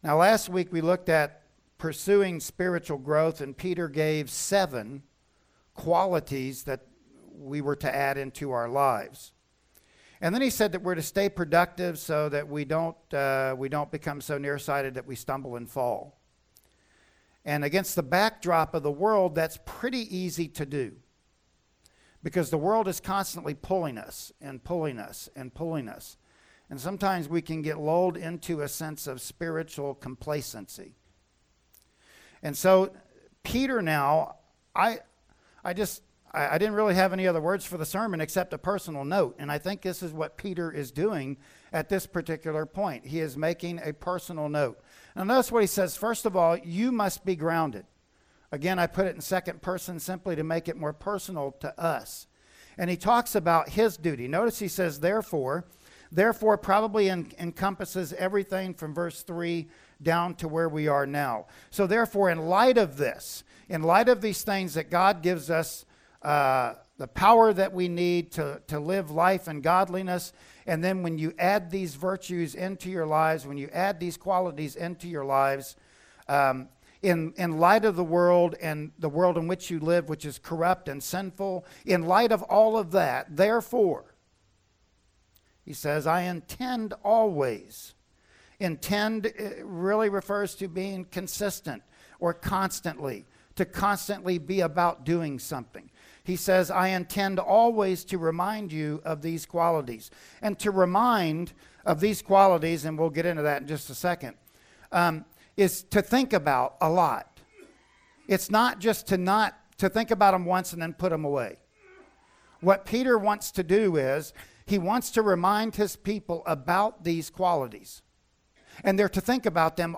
"2 Peter 1:12-15" Service Type: Sunday Morning Worship Service Bible Text